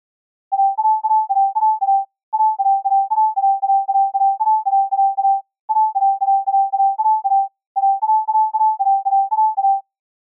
Rhinoで、sine波の一つだけのOscillatorでPitchを+12にし、Filterなし、Effectなしの状態をベースとして、
各巻ごとにOscillatorのlevel envelopeのみを変えています。
先頭に0.5秒の無音部を入れています。